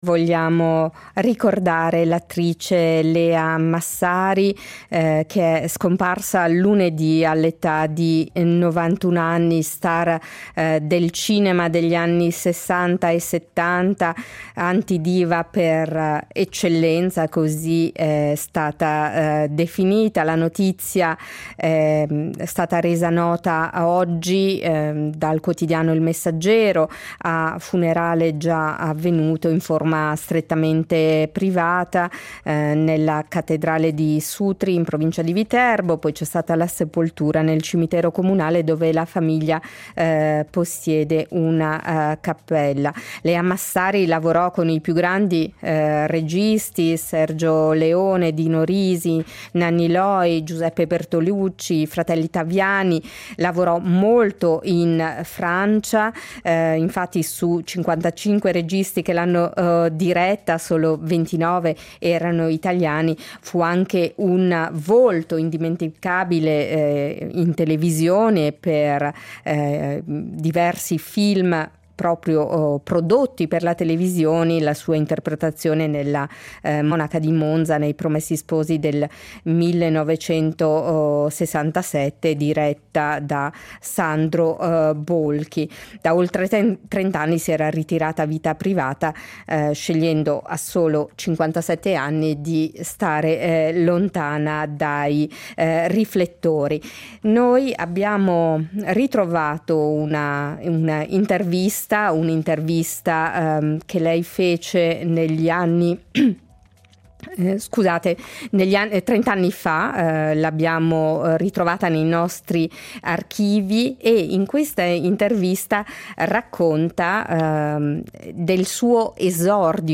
Riascoltiamo la voce dell’attrice scomparsa lunedì in un’intervista di trent’anni fa degli archivi RSI .